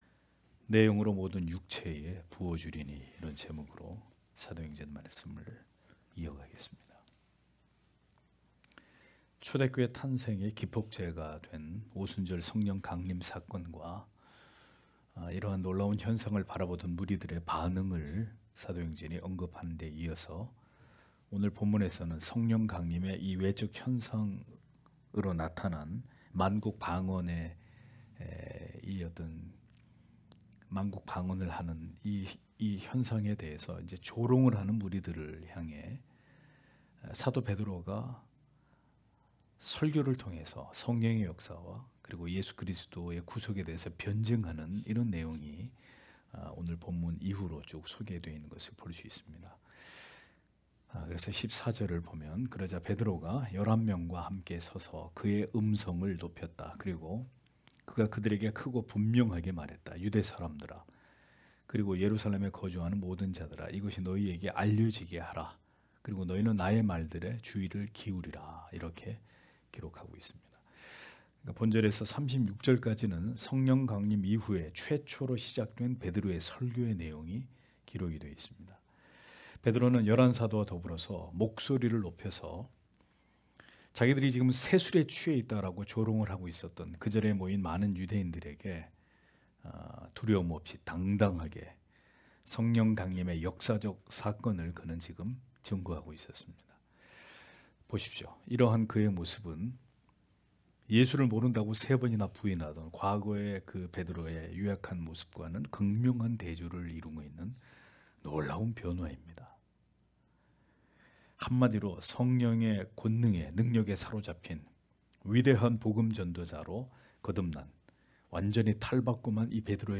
금요기도회 - [사도행전 강해 11] 내 영으로 모든 육체에게 부어 주리니 (행 2장 14- 17절)